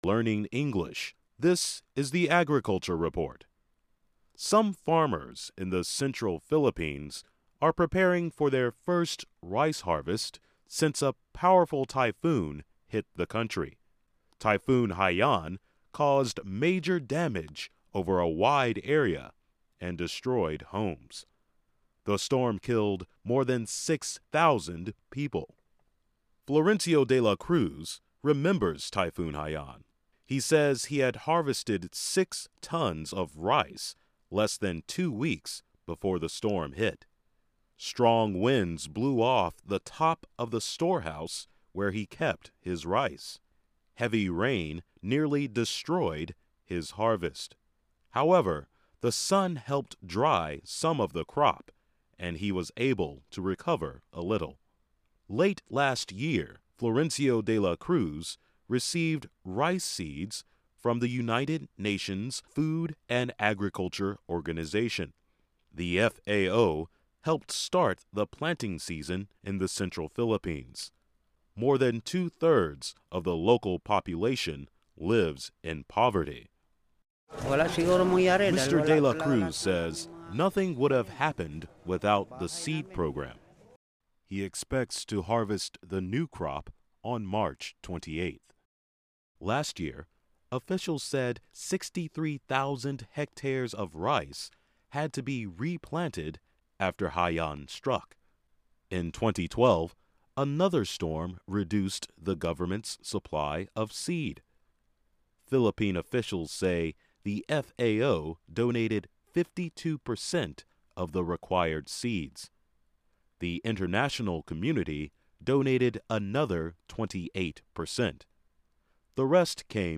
Learn English as you read and listen to a weekly show about farming, food security in the developing world, agronomy, gardening and other subjects. Our stories are written at the intermediate and upper-beginner level and are read one-third slower than regular VOA English.